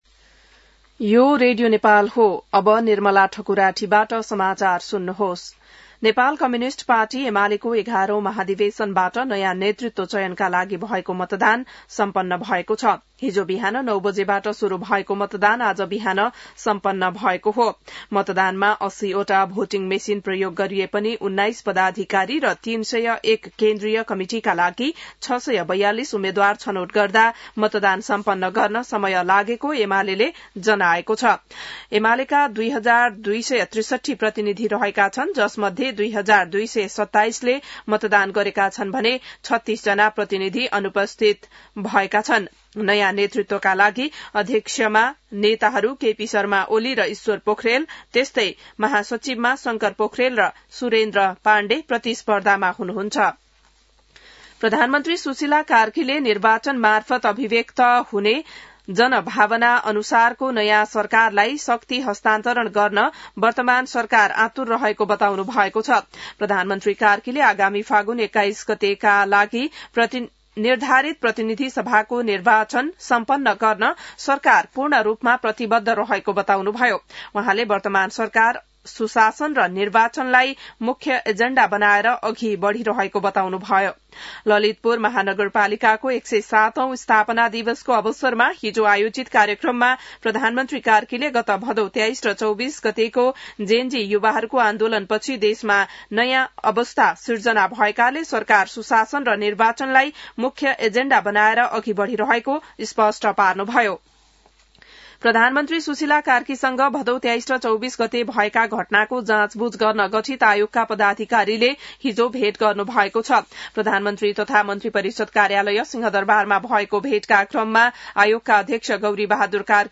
बिहान १० बजेको नेपाली समाचार : ३ पुष , २०८२